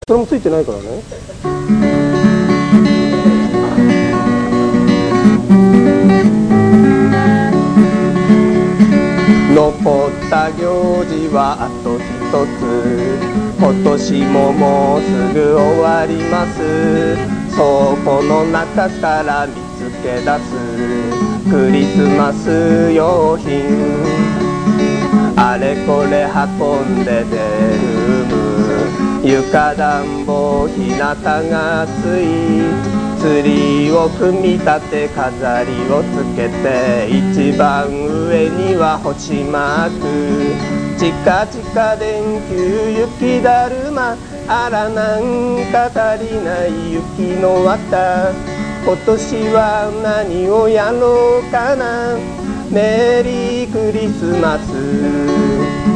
久し振りにライブをやりました。
ステージの時には喉が嗄れて、声が変になってました。